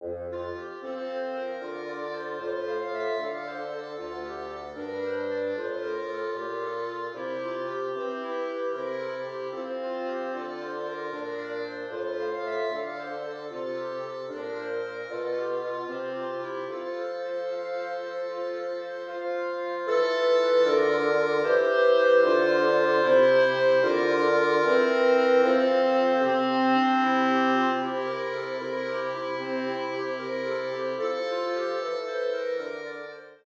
für 2 Klarinetten und Fagott/Bassetthorn/Bassklarinette